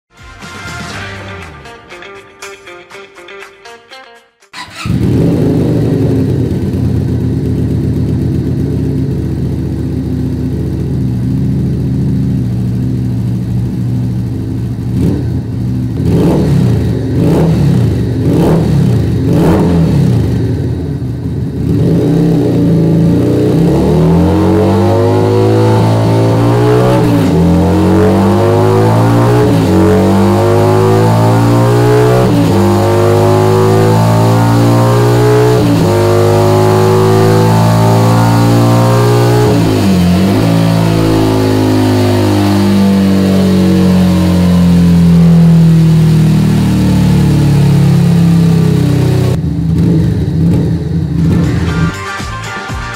Keeway SCR 250 V Twin sound effects free download
Keeway SCR 250 V-Twin - EVOX X3NRB